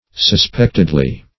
-- Sus*pect"ed*ly , adv.
suspectedly.mp3